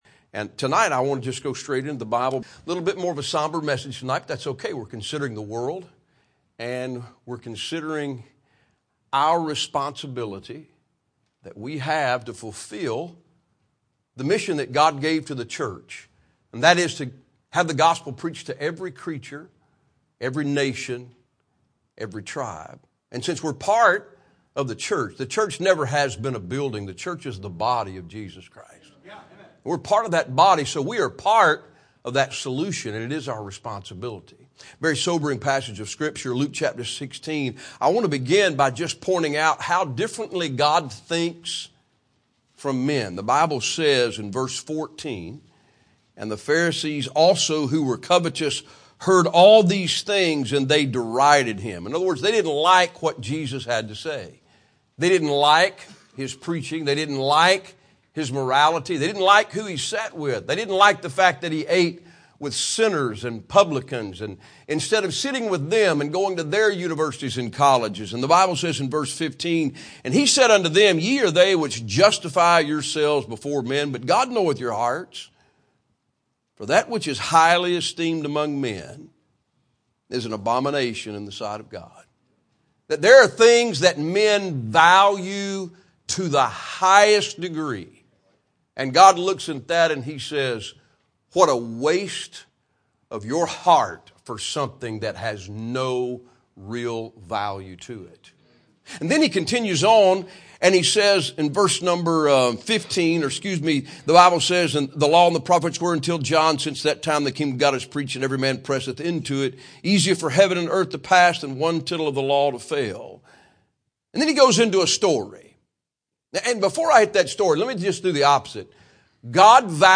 Sermons
missions conference